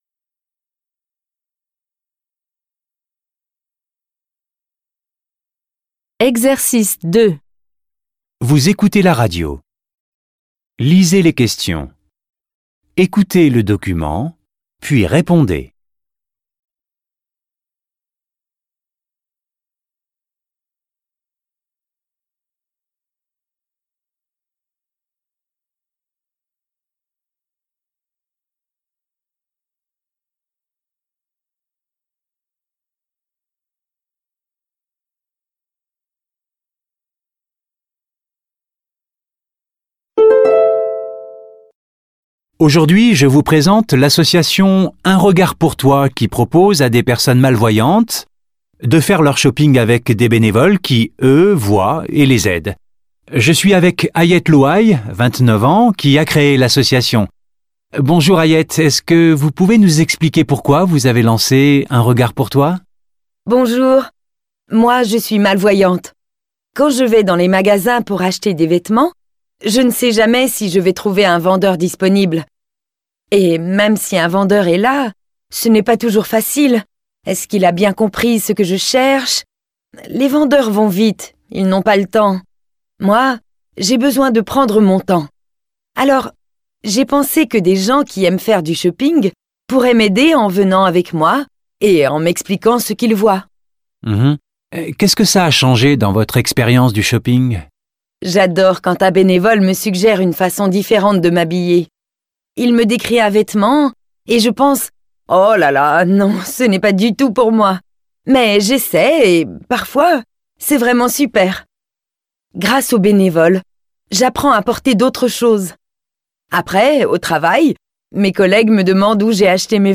• Exercice 2 : une émission de radio sur un sujet professionnel.